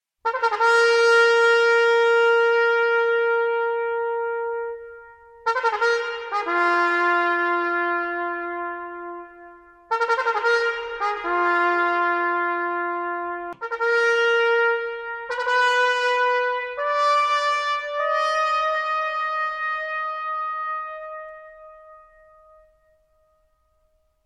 4. Por último, o posto estrela lévao a , o instrumento máis pequeno e polo tanto co rexistro máis da familia, presente en multitude de xéneros musicais.
trompeta3.mp3